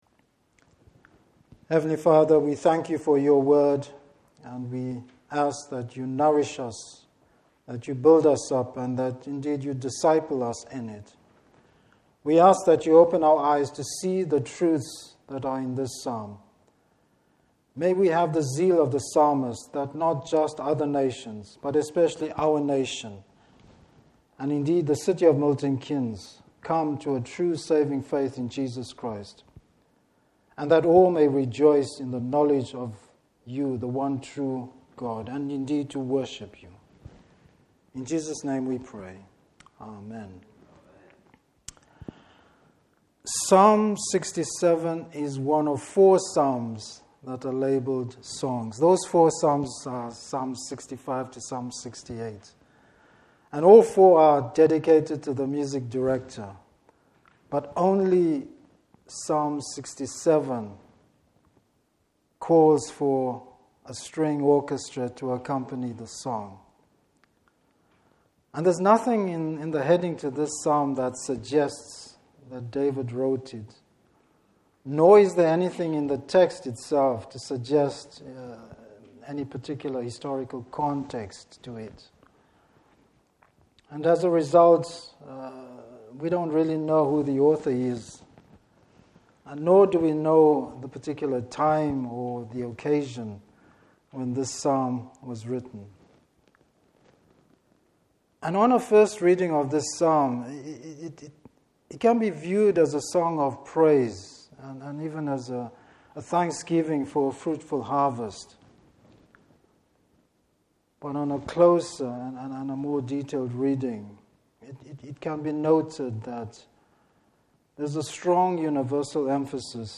Service Type: Evening Service The missionary God!